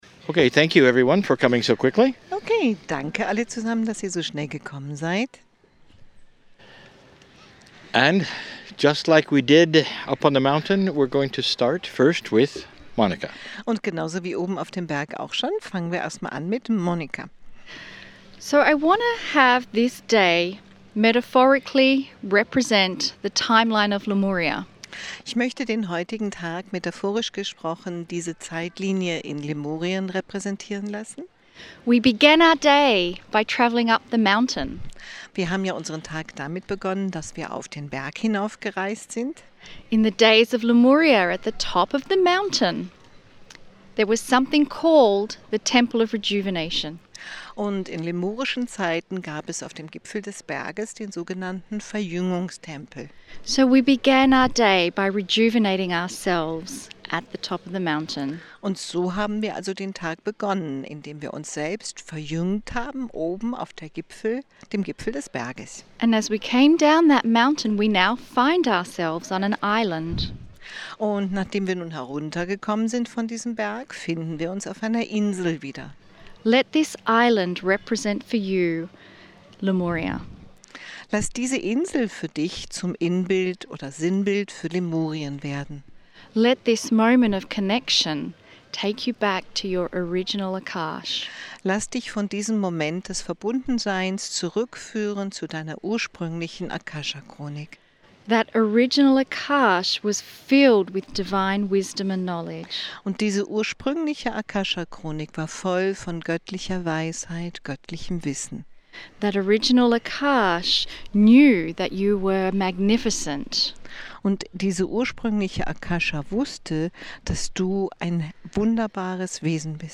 The Bodensee Excursion - Germany - Sept 16 2018
KRYON CHANNELLING THE FIVE CIVILIZATIONS